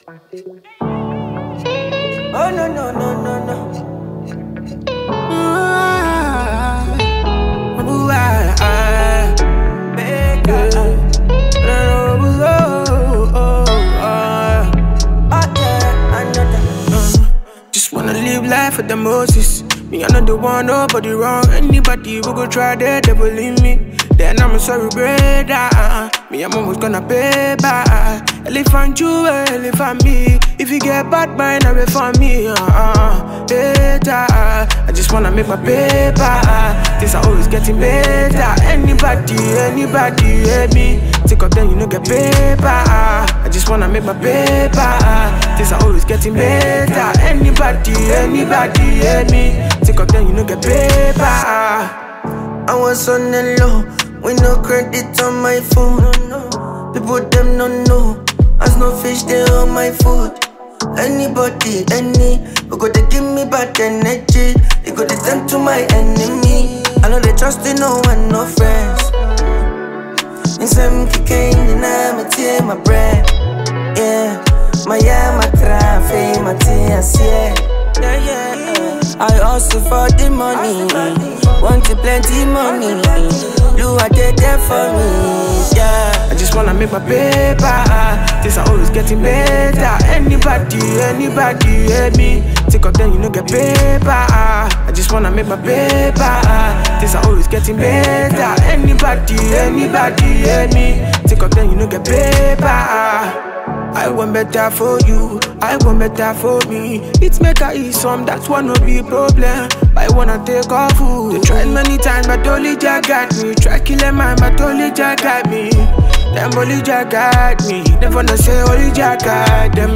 soulful and reflective new single
smooth vocals and spiritual grace